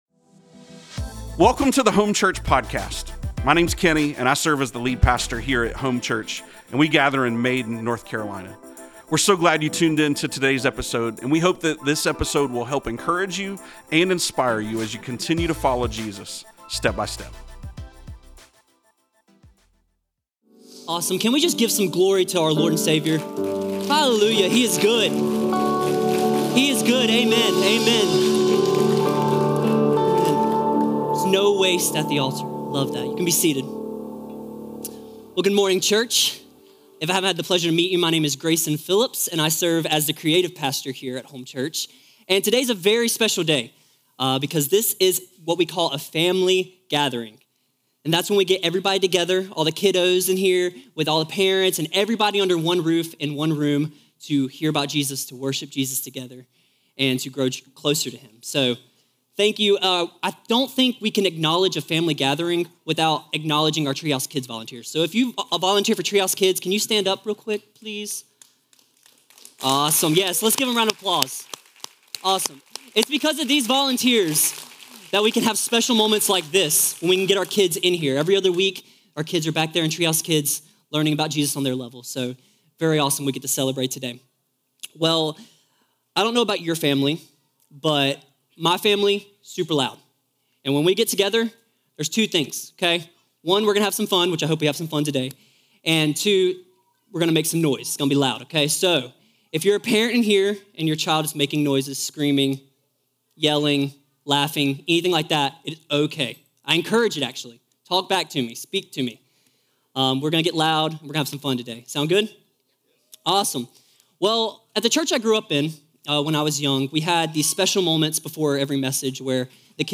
We love family gathering Sundays!